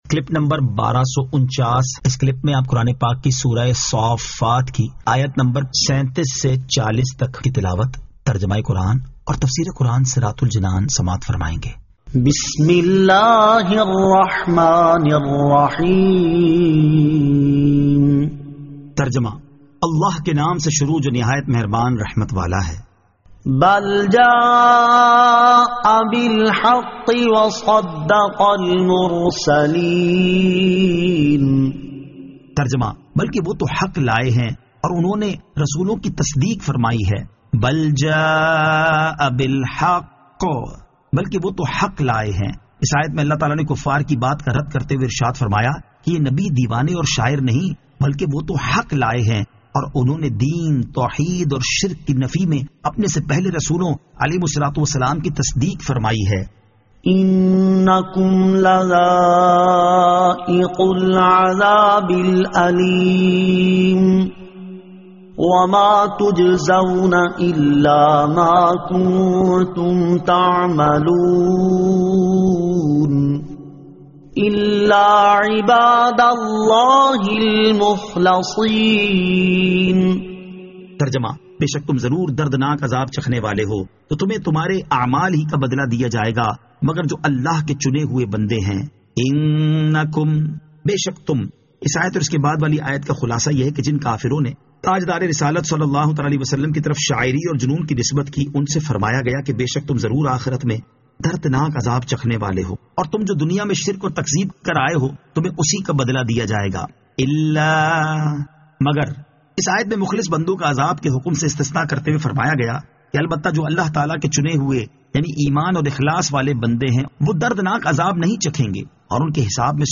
Surah As-Saaffat 37 To 40 Tilawat , Tarjama , Tafseer